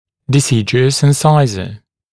[dɪ’sɪdjuəs ɪn’saɪzə][ди’сидйуэс ин’сайзэ]молочный резец